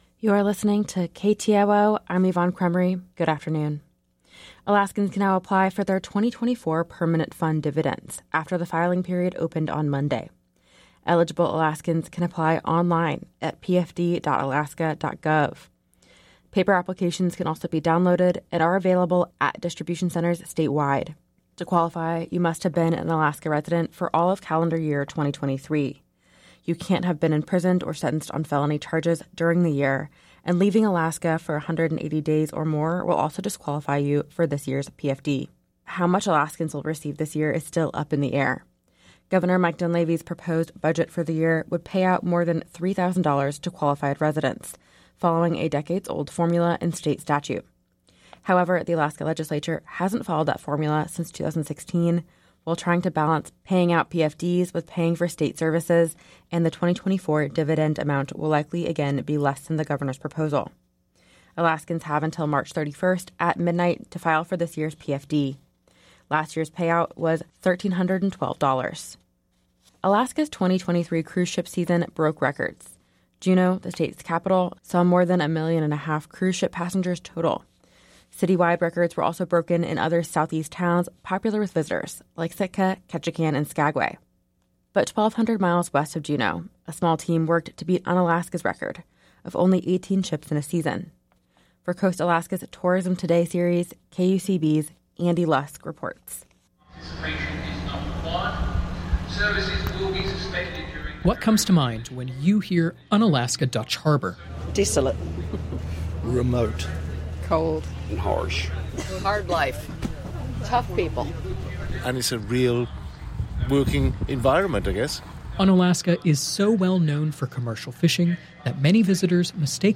Newscast – Tuesday. Jan. 2 2024